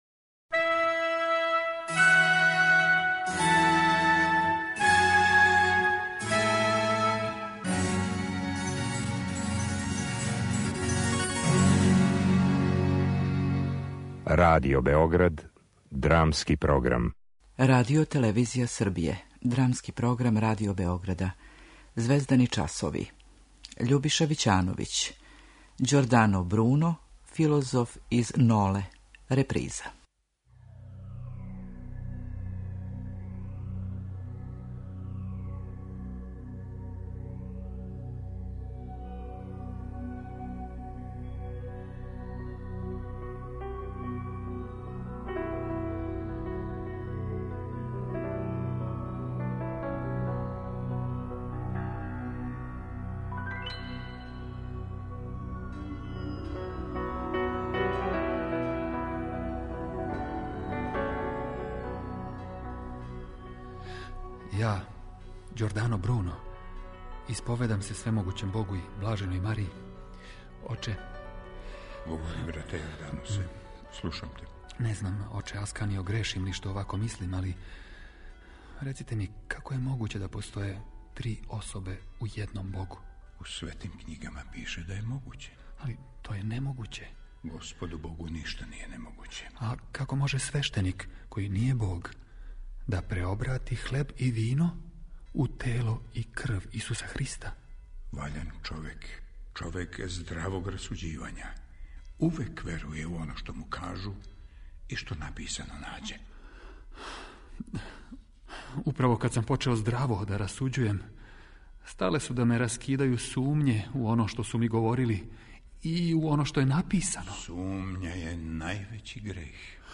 Драмски програм: Звездани часови